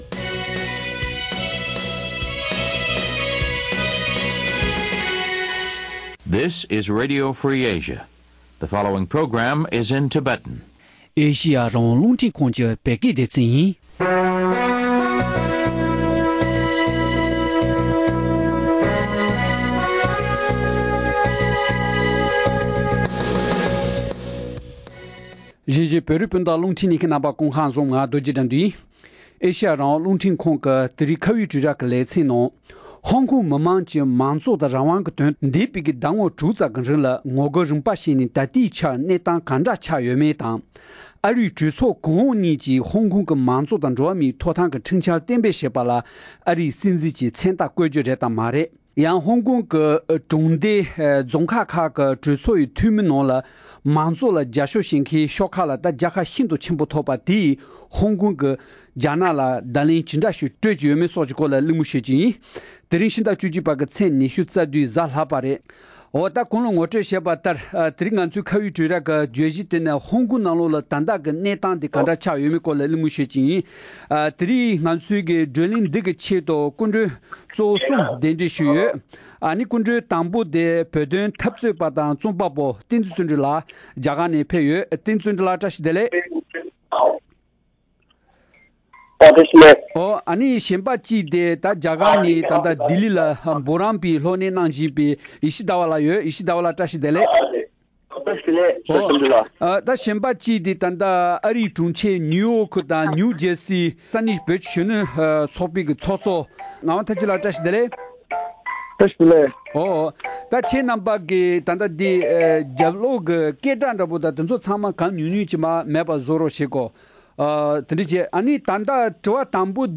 ཧོང་ཀོང་གི་ད་ལྟའི་གནས་སྟངས་དང་མ་འོངས་ཁ་ཕྱོགས་སོགས་ཀྱི་ཐད་གླེང་མོལ།